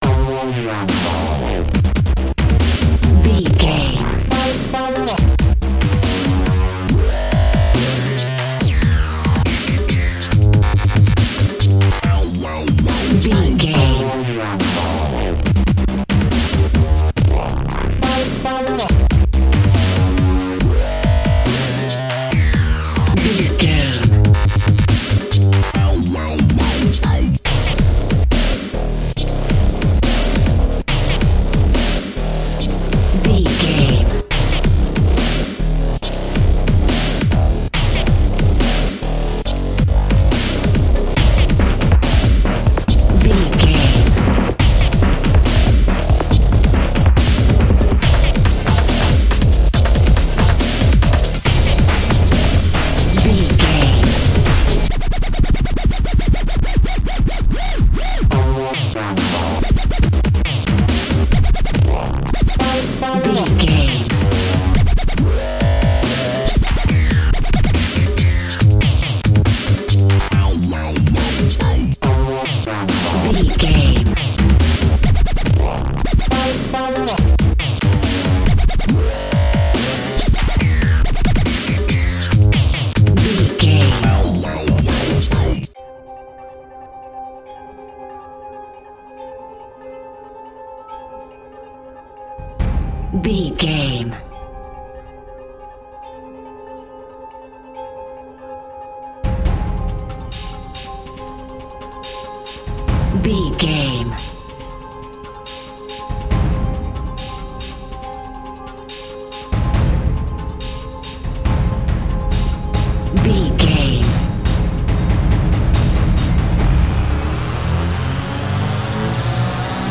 Aeolian/Minor
strings
drums
percussion
synthesiser
drum machine
orchestral
orchestral hybrid
dubstep
aggressive
energetic
intense
bass
synth effects
wobbles
driving drum beat
epic